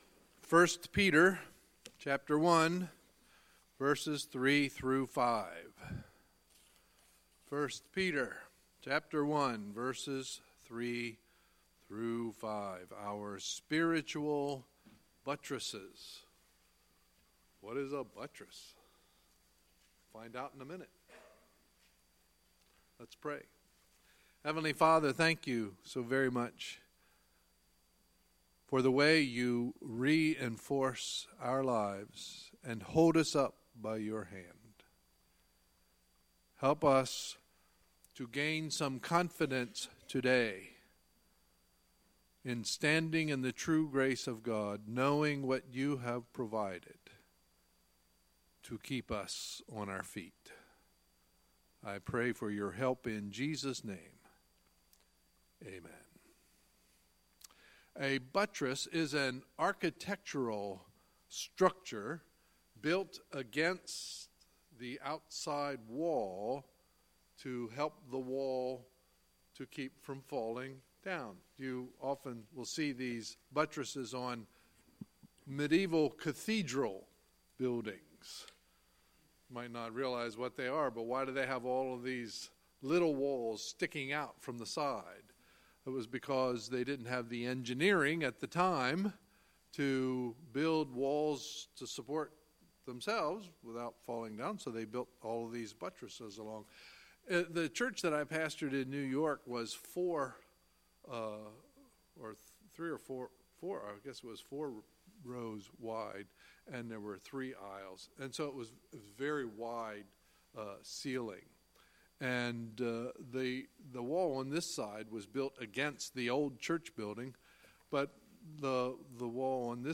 Sunday, January 21, 2018 – Sunday Morning Service